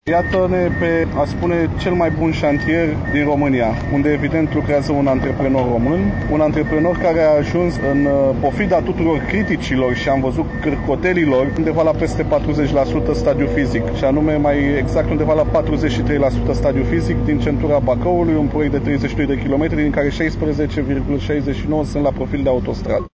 (AUDIO/FOTO) Vizită a ministrului Transporturilor pe șantierul Variantei Ocolitoare Bacău